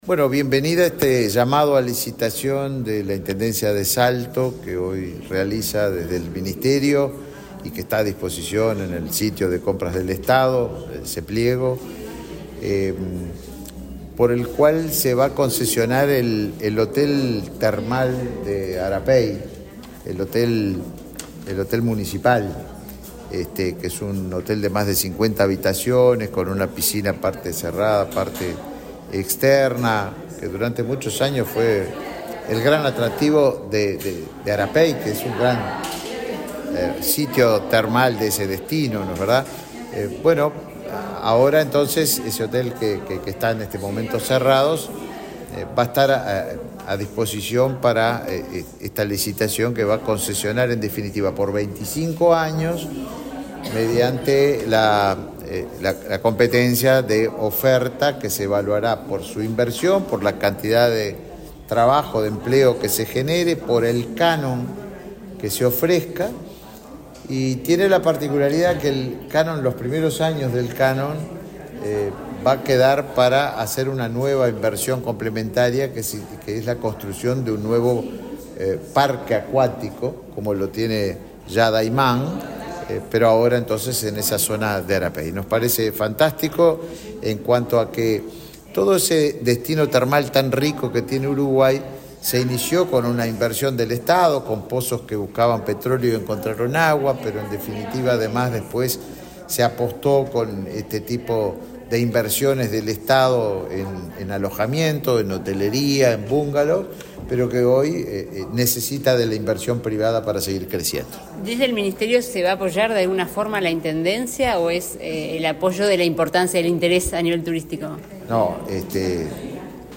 Declaraciones del ministro de Turismo, Tabaré Viera
Declaraciones del ministro de Turismo, Tabaré Viera 21/12/2023 Compartir Facebook X Copiar enlace WhatsApp LinkedIn El ministro de Turismo, Tabaré Viera, participó, este jueves 21 en la sede de la cartera que dirije, en la presentación del llamado a licitación para la explotación comercial del Hotel Municipal de Termas del Arapey en Salto. Luego, dialogó con la prensa.